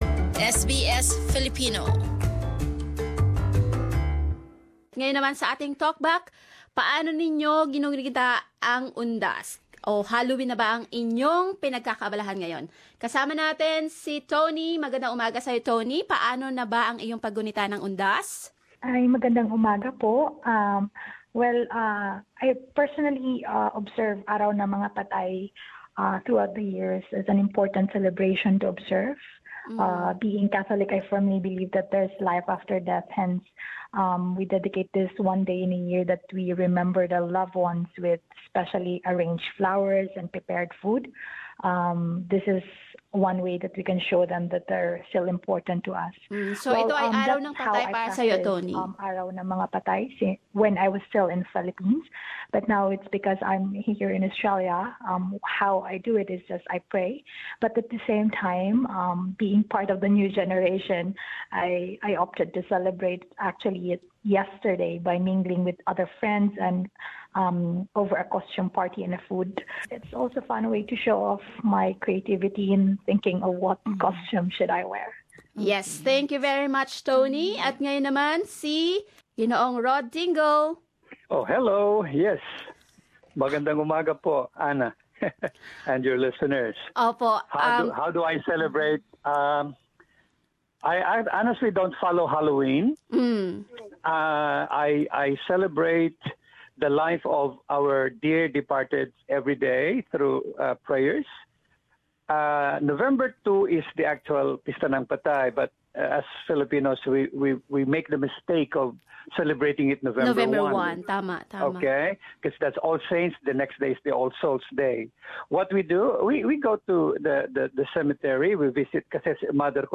Do you remember your departed loved ones or attend Halloween parties? Hear from two of our listeners sharing their thoughts.